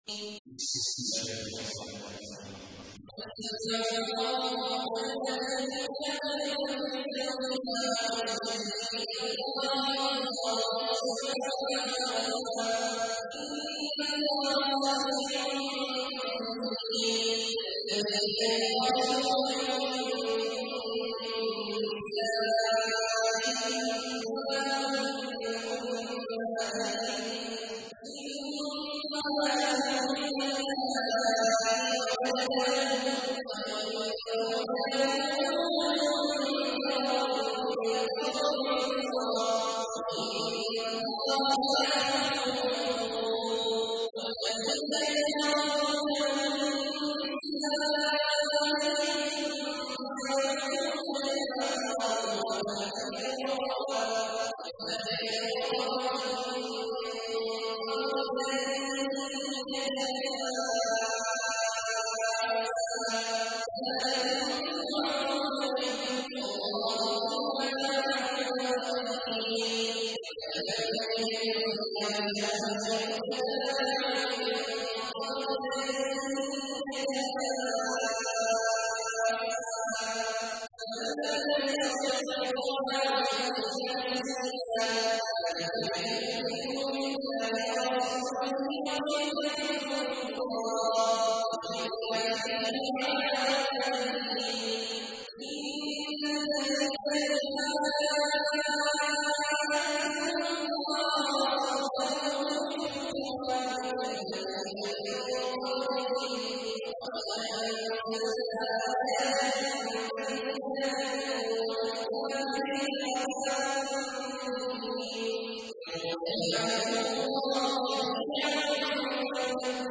تحميل : 58. سورة المجادلة / القارئ عبد الله عواد الجهني / القرآن الكريم / موقع يا حسين